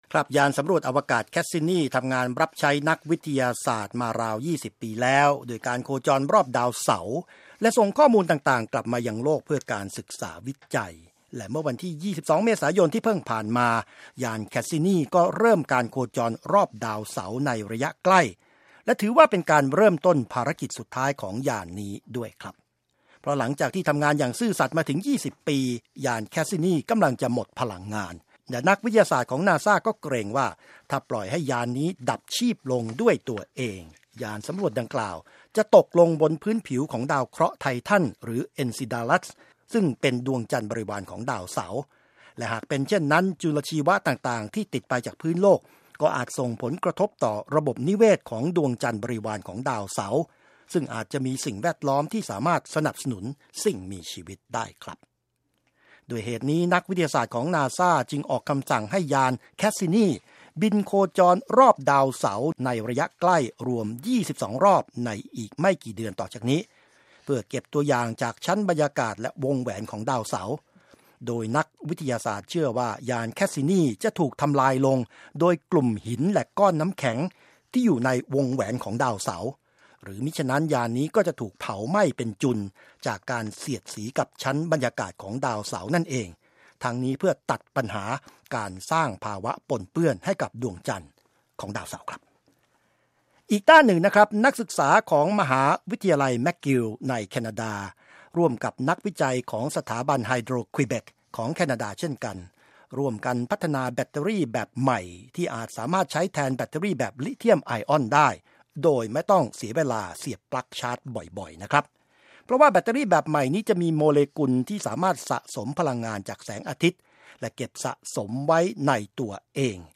สรุปรายงานวิทยาศาสตร์